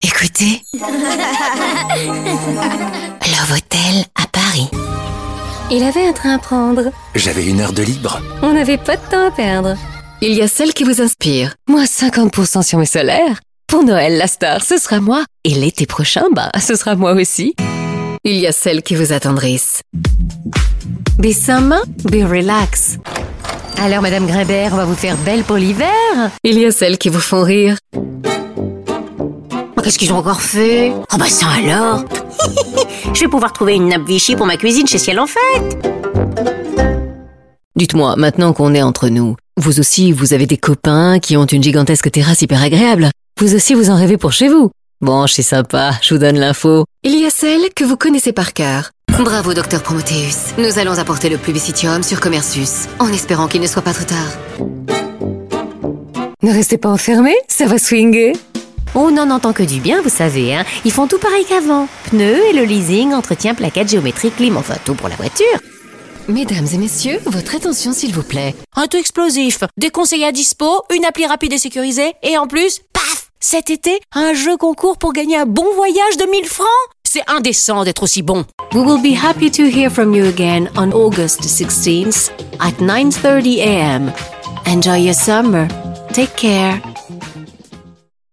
Principaux : Comédien·ne - Doublage / voix off